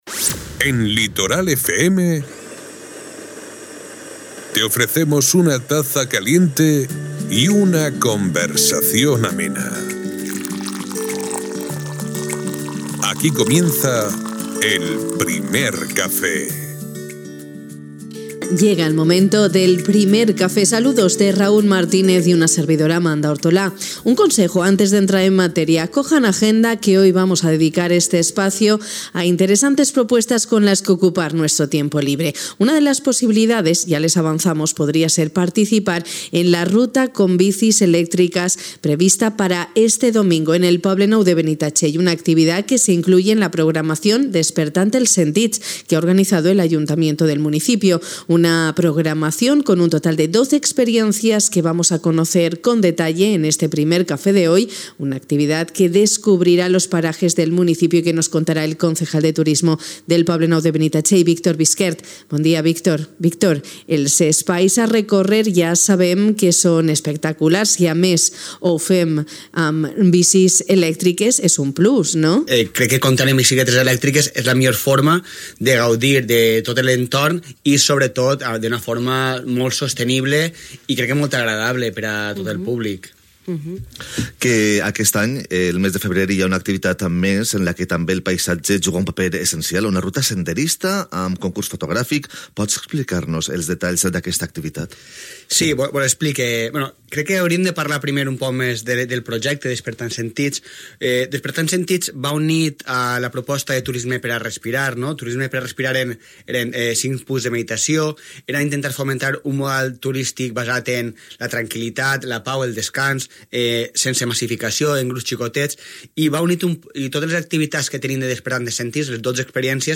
Una iniciativa de caràcter anual, que pretén crear experiències úniques per a veïns i visitants, que hem conegut amb Víctor Bisquert, regidor de Turisme.